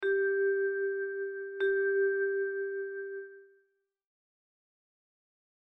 • Durante a primeira melodía soa case todo o tempo este motivo rítmico, agás nos finais de compás:
ritmo_melodia_2.mp3